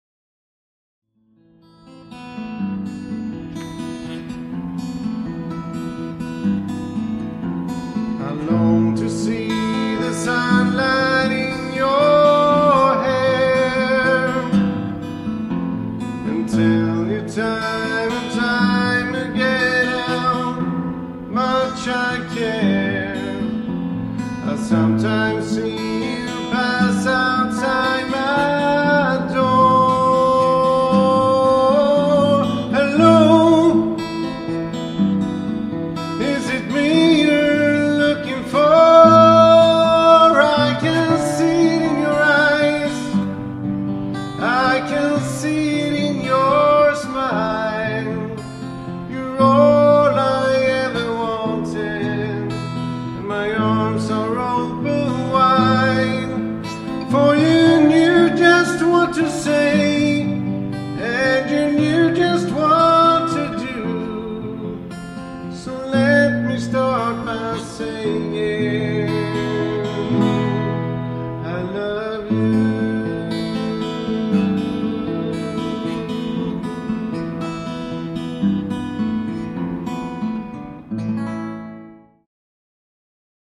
• Duo/trio